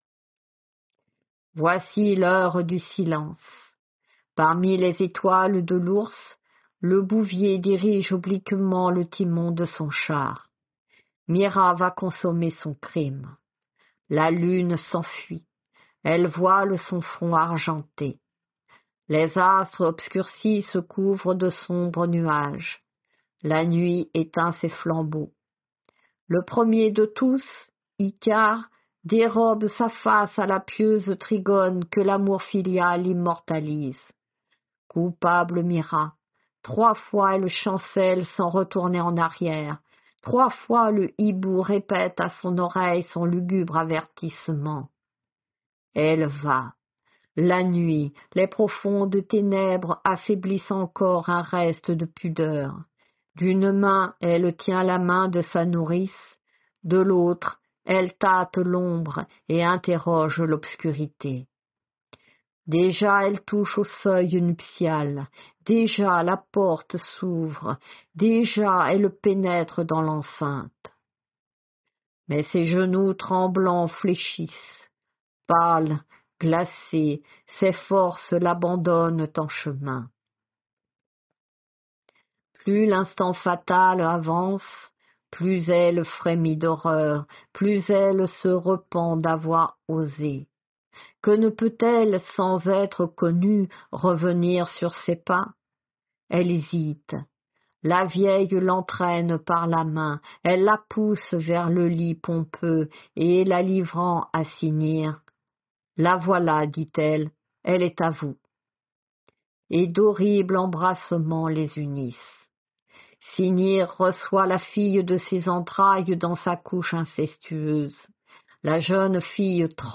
Lecture de la métamorphose de Myrrha · GPC Groupe 1